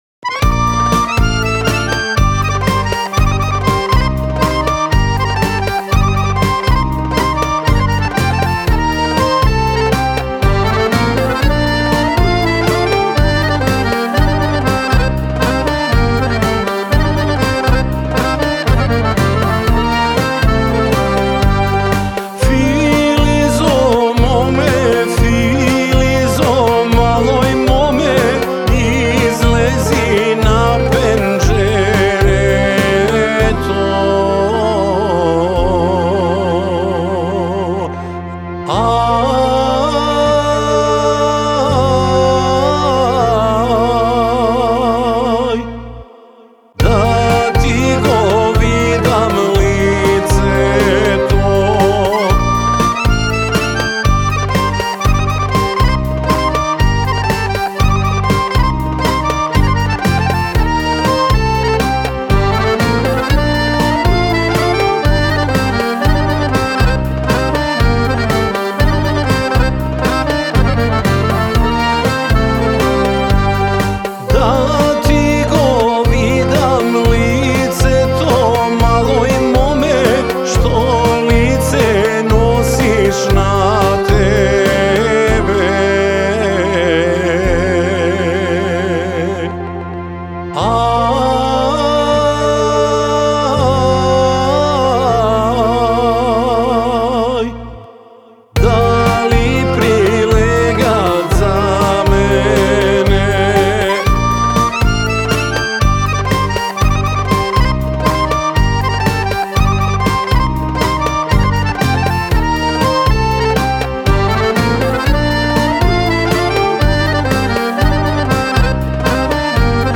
народна македонска песна